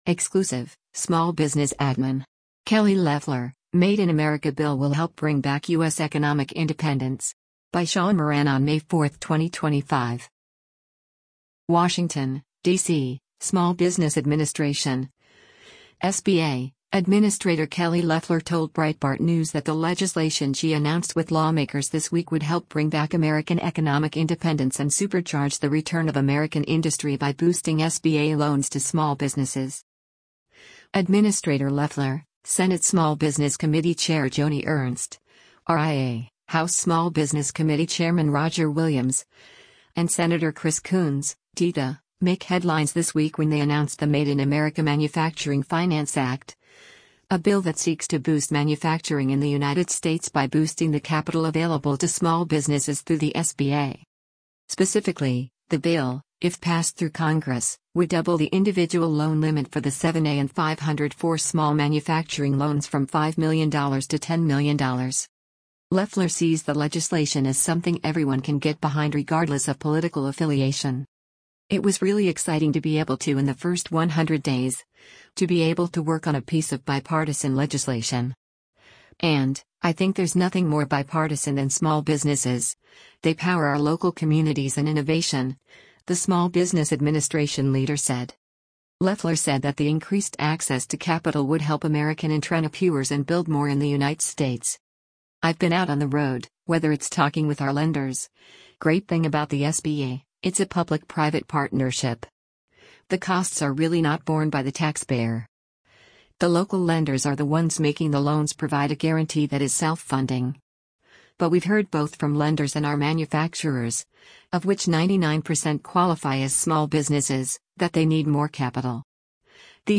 Follow Breitbart News for more pieces on Breitbart News’s interview with Small Business Administrator Loeffler.